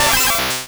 Cri de Kabutops dans Pokémon Rouge et Bleu.